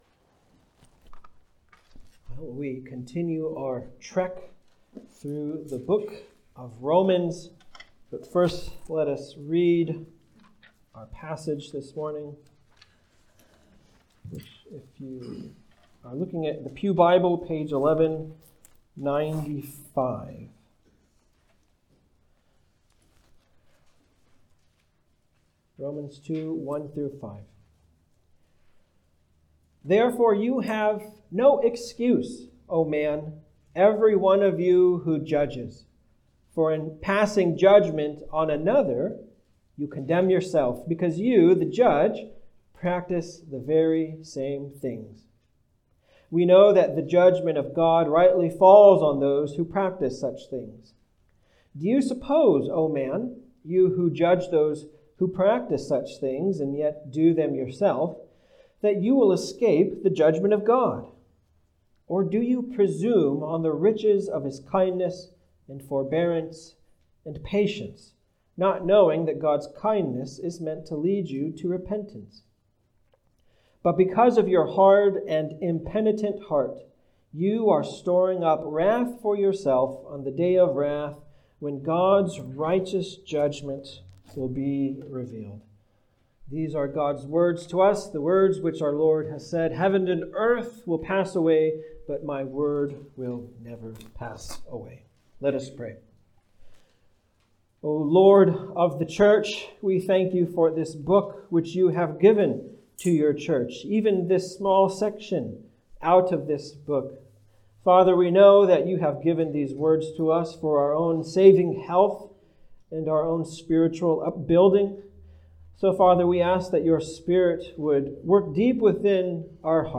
Passage: Romans 2:1-5 Service Type: Sunday Service « Consequences Is God Enough?